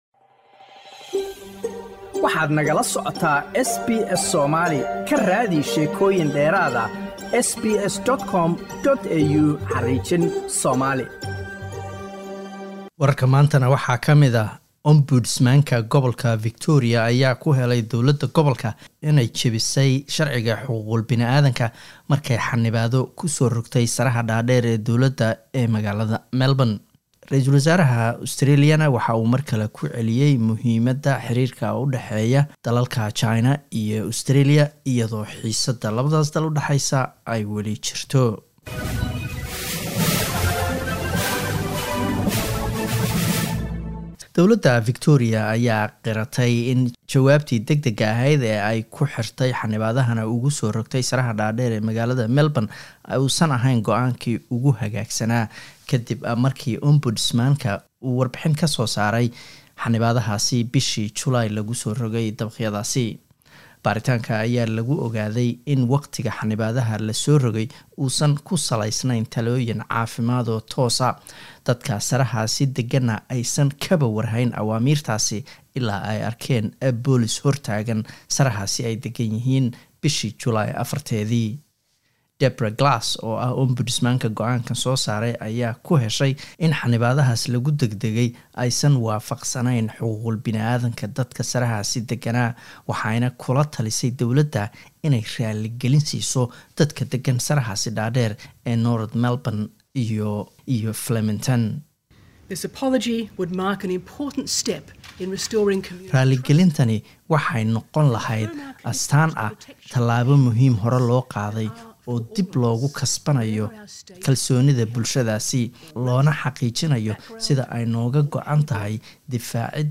Wararka SBS Somali Khamiis 17 Disember